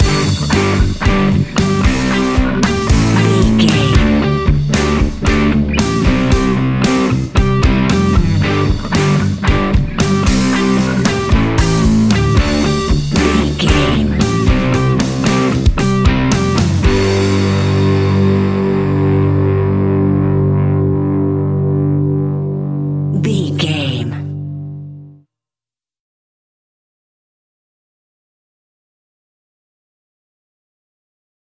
Epic / Action
Fast paced
Ionian/Major
hard rock
blues rock
distortion
rock guitars
Rock Bass
Rock Drums
distorted guitars
hammond organ